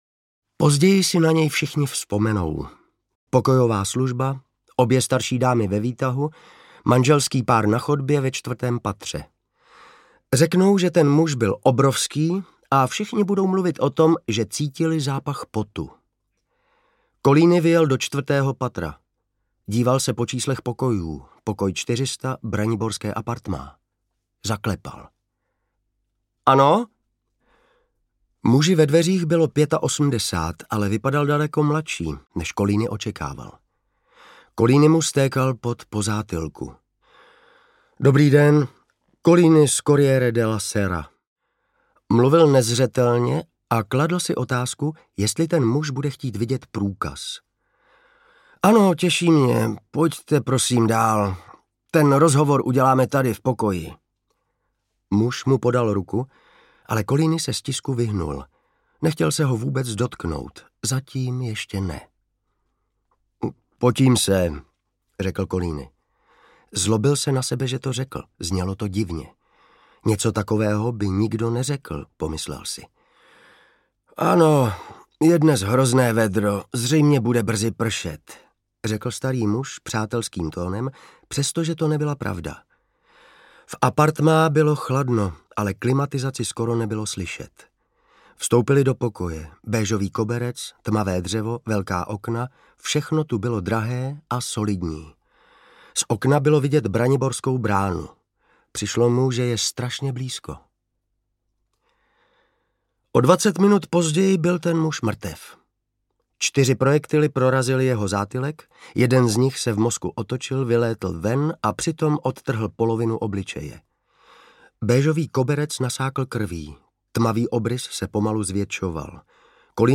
Případ Collini audiokniha
Ukázka z knihy
Vyrobilo studio Soundguru.